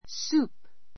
soup 小 A1 súːp ス ー プ 名詞 スープ eat soup eat soup （スプーンで）スープを飲む drink soup from a cup drink soup from a cup カップからスープを飲む I have soup every day.